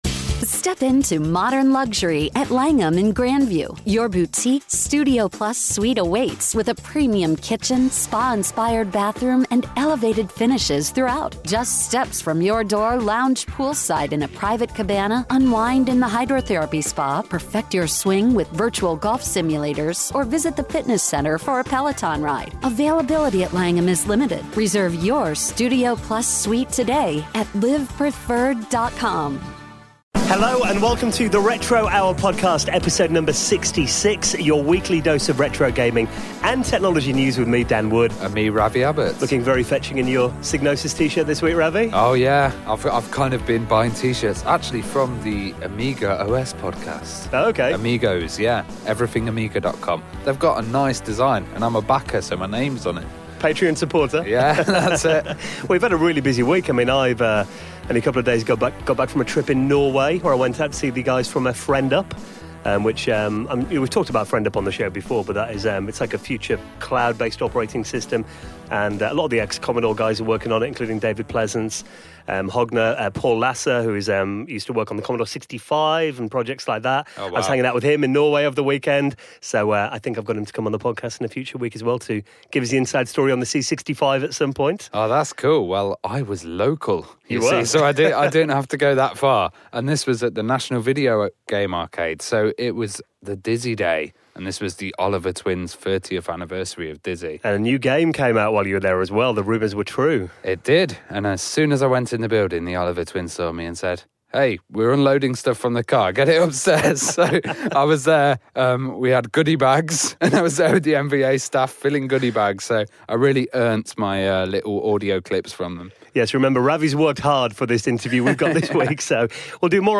Interview
at the launch of Mystery World Dizzy